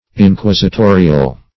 Inquisitorial \In*quis`i*to"ri*al\, a. [Cf. F. inquisitorial.]